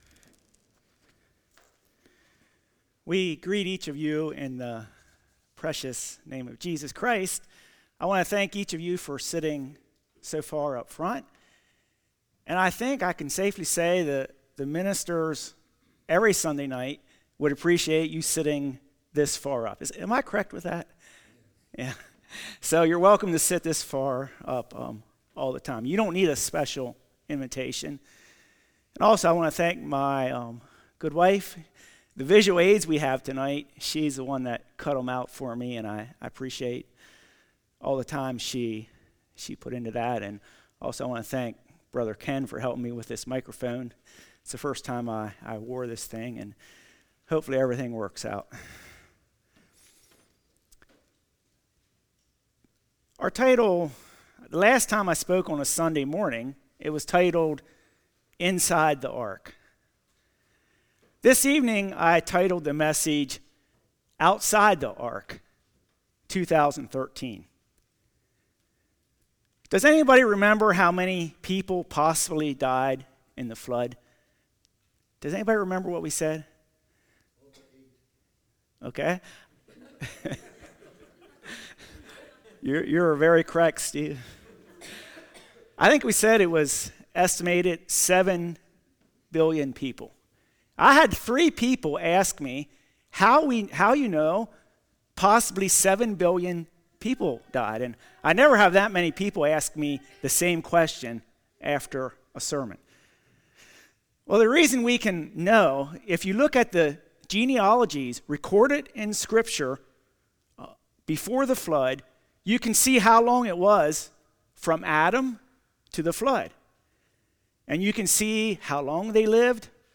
John 10:1-9 Service Type: Evening 7 billion people Door of Works Grace & Faith « Joshua